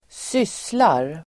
Uttal: [²s'ys:lar]